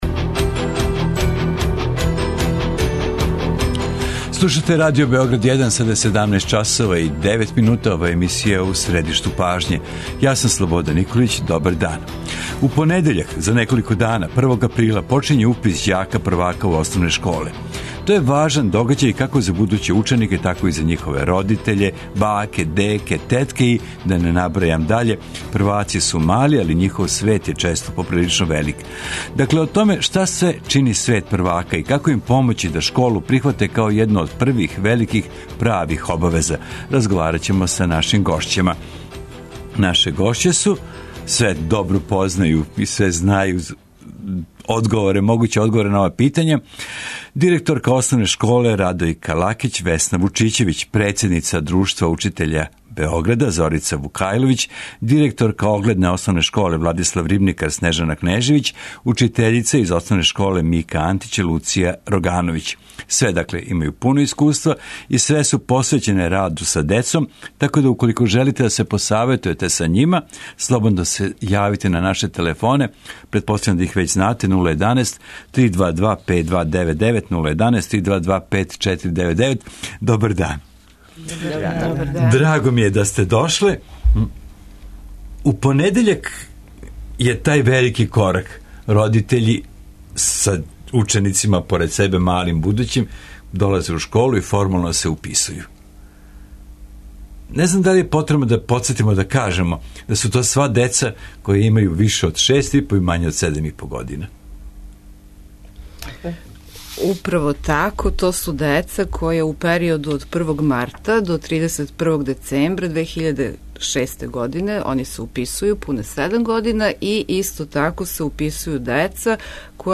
Гошће емисије су све искусни педагози, наставници, школски психолози, директори основних школа.